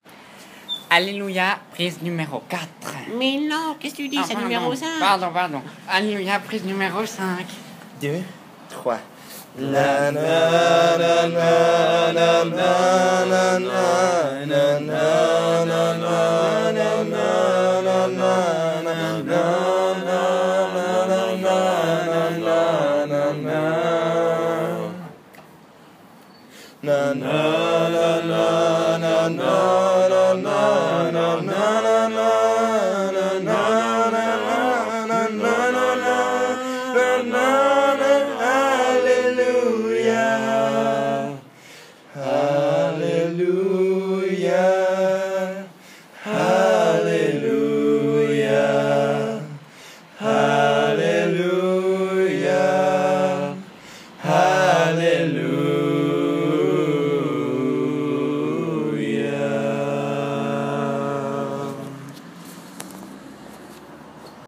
A capella, le retour